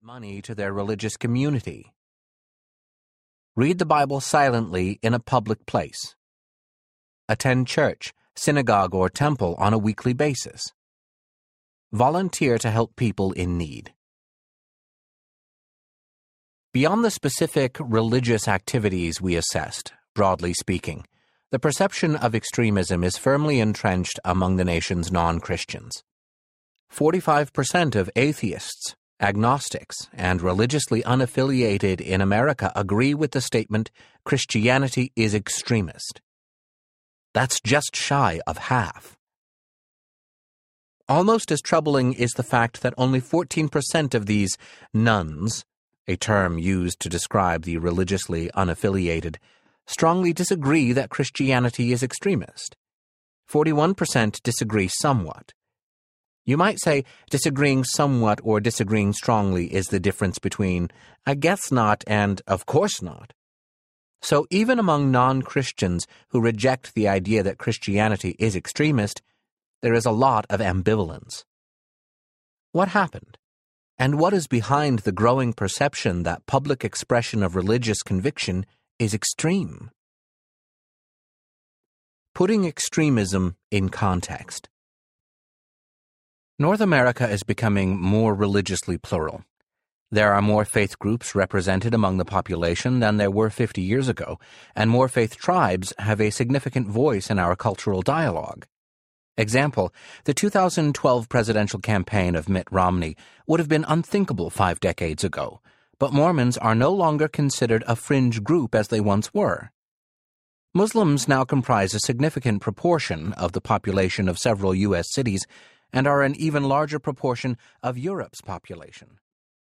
Good Faith Audiobook
7.38 Hrs. – Unabridged